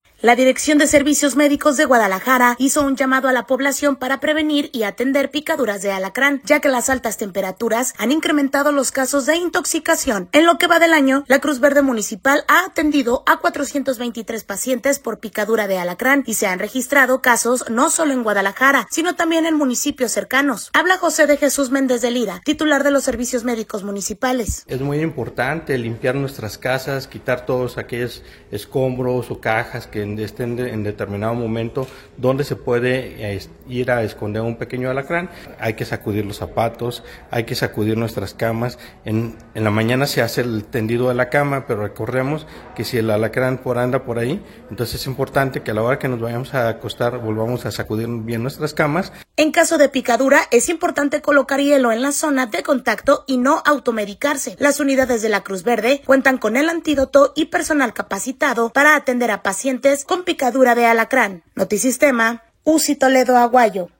Habla José de Jesús Méndez de Lira, titular de los Servicios Médicos Municipales.